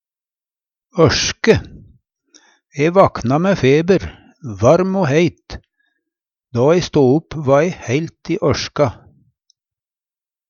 ørske - Numedalsmål (en-US)